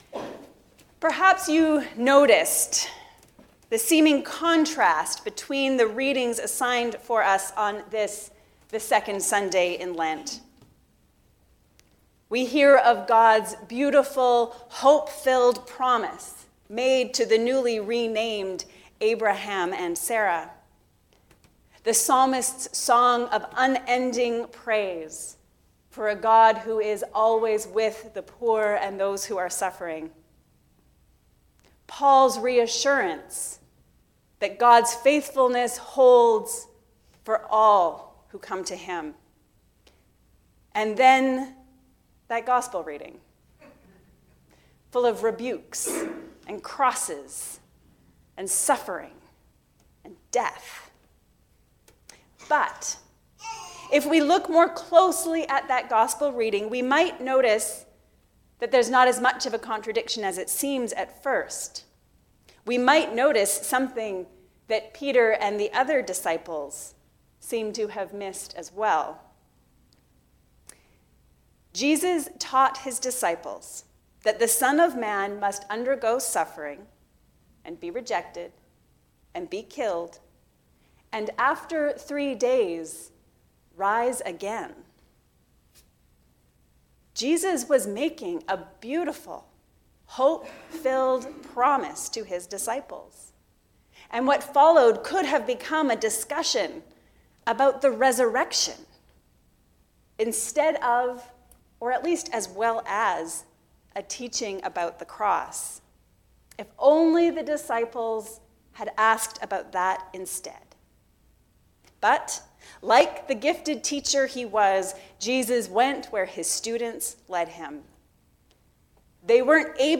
A sermon for the 2nd Sunday in Lent on Mark 8:31-38